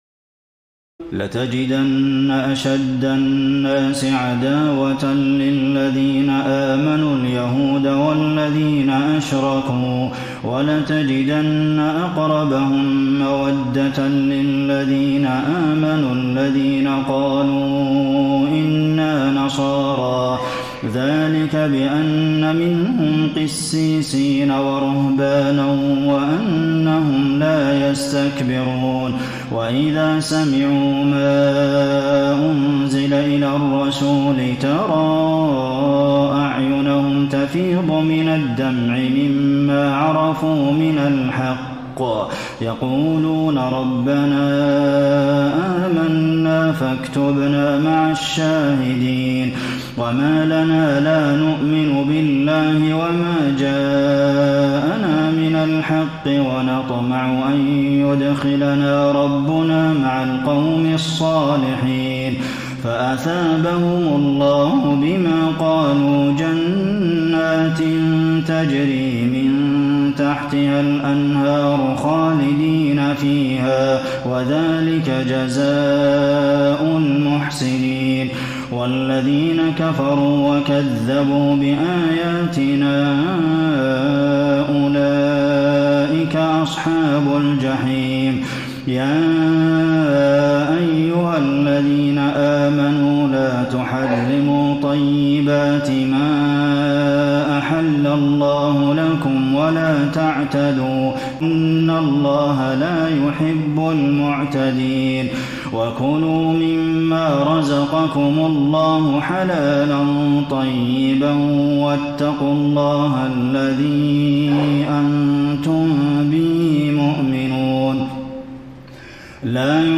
تراويح الليلة السابعة رمضان 1433هـ من سورتي المائدة (82-120) و الأنعام (1-36) Taraweeh 7 st night Ramadan 1433H from Surah AlMa'idah and Al-An’aam > تراويح الحرم النبوي عام 1433 🕌 > التراويح - تلاوات الحرمين